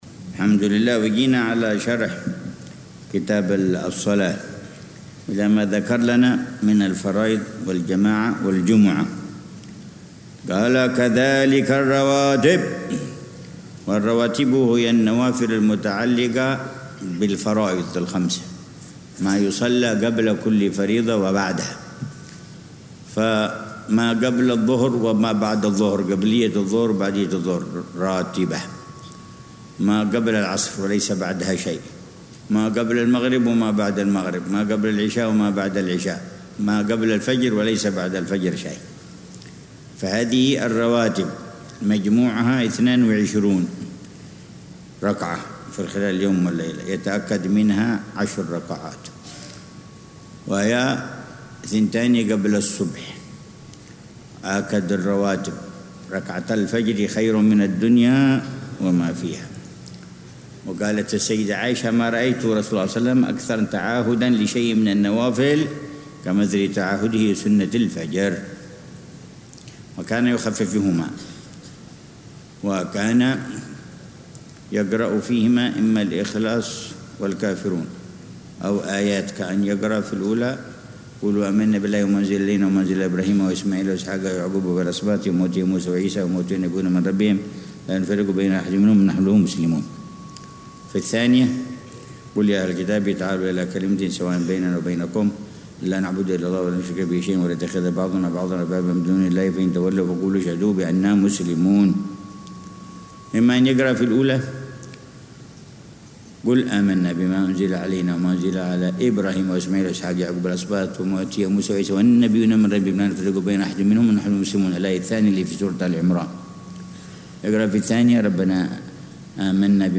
الدرس الحادي والعشرون ( 11 صفر 1447هـ)